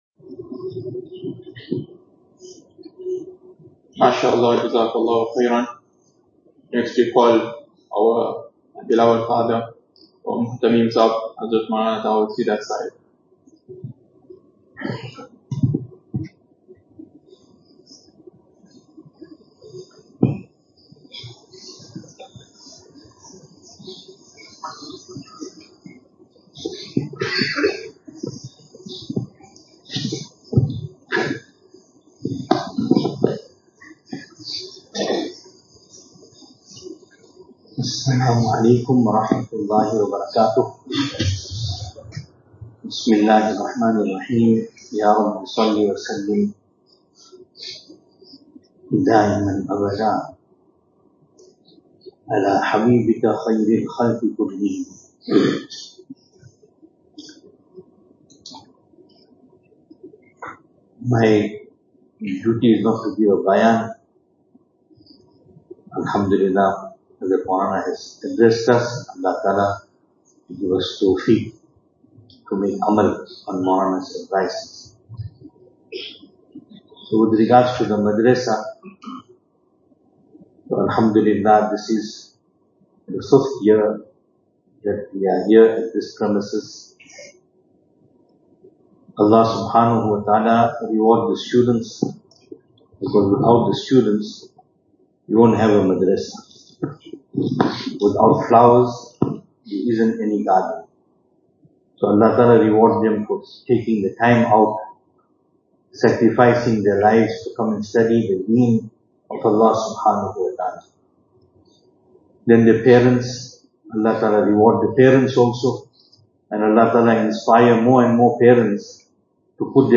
2025-12-20 Madrasah Hifz Jalsah Advices Venue: Albert Falls , Madressa Isha'atul Haq Service Type: Majlis « How is Tazkiyah undertaken ?